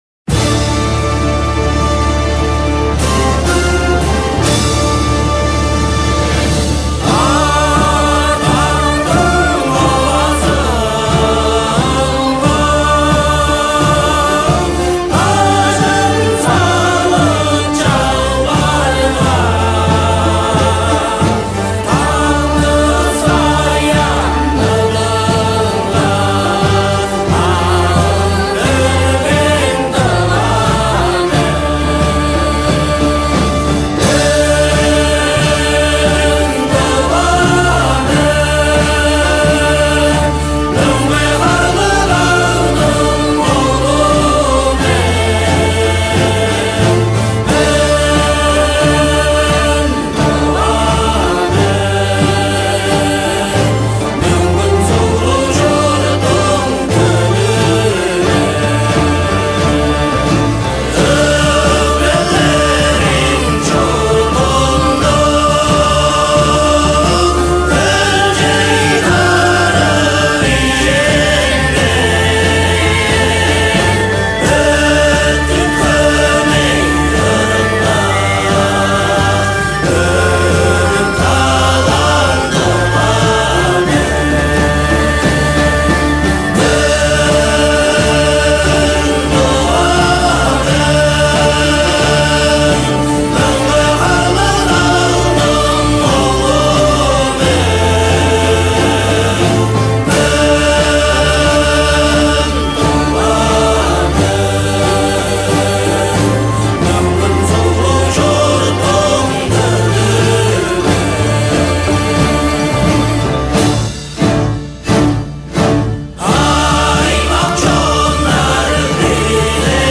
в живом вокальном исполнении